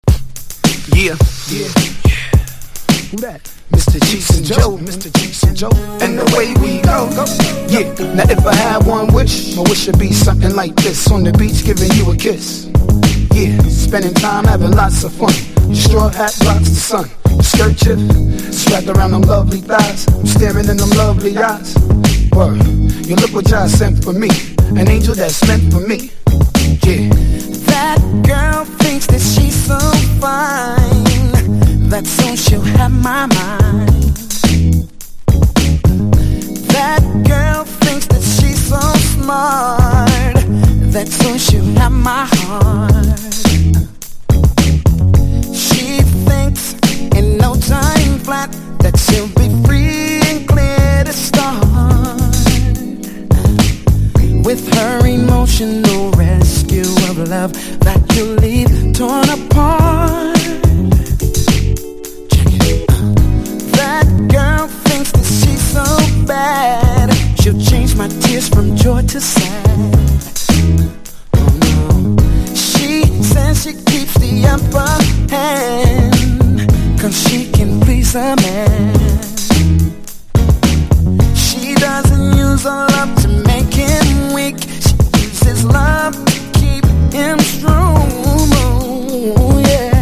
• R&B